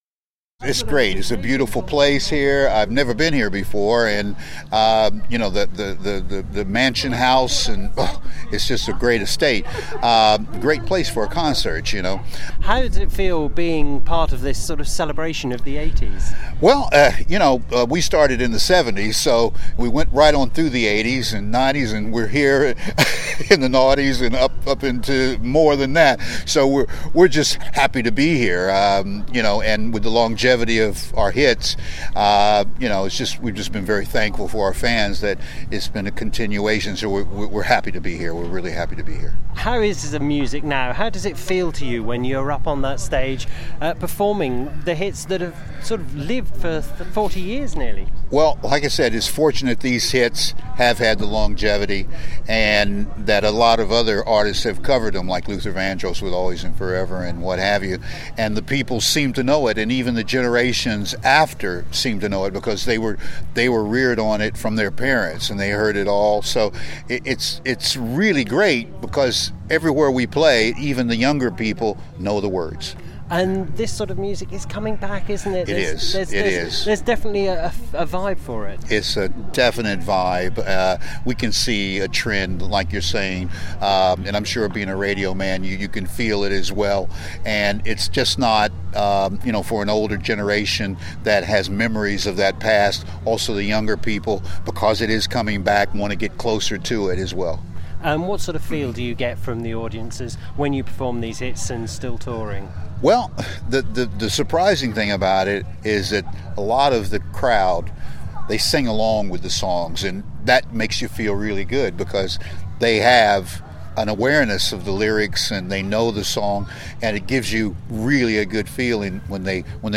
The North Norfolk Radio team were at Holkham Hall for the celebration of the 80’s event.